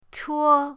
chuo1.mp3